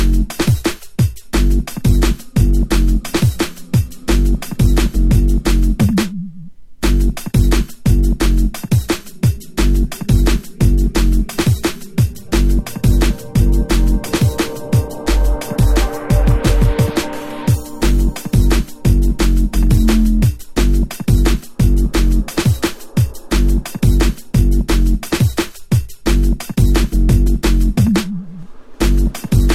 TOP >Vinyl >Drum & Bass / Jungle